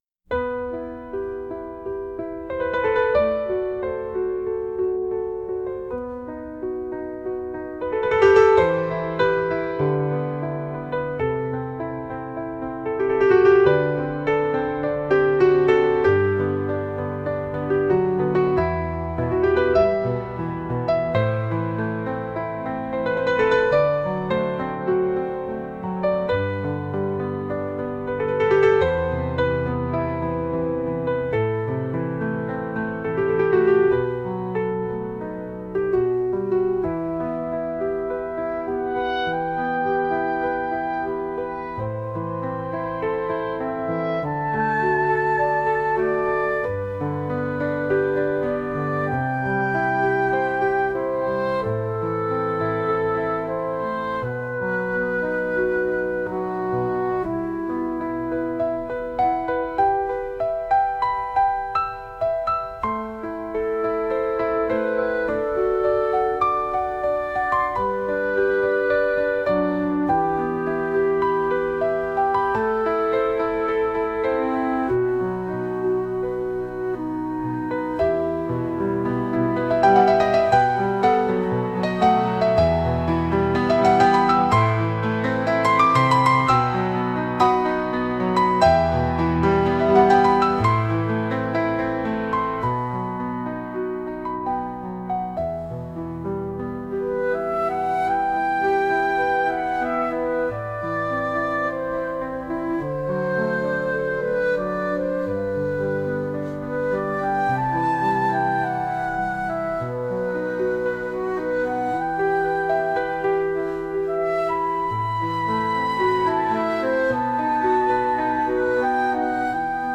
Flauto di Pan a aa strumenti musicali
MUSICA CLASSICA